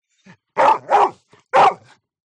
Агрессивный пес лает трижды